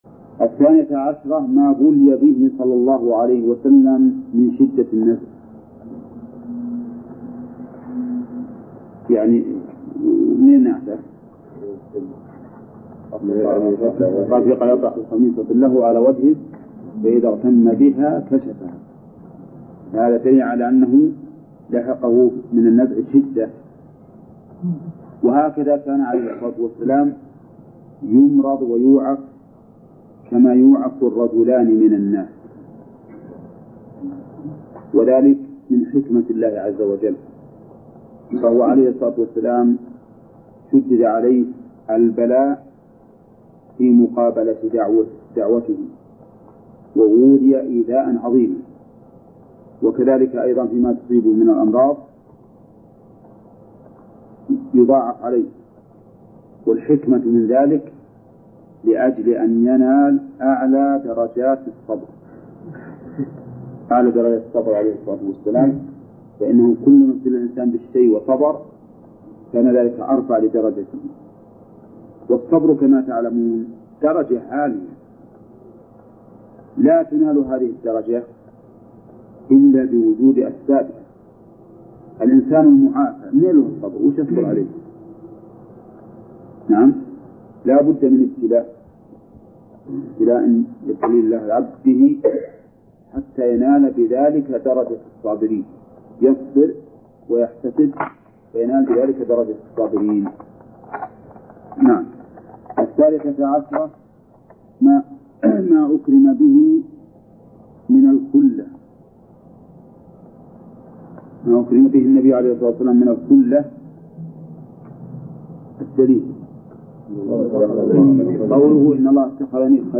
درس (20) : من صفحة: (416)، قوله: (الثانية عشر: مابلي به ‘ من شدة النزع).، إلى : صفحة: (437)، قوله: (باب ما جاء في حماية المصطفى ‘ جناب التوحيد).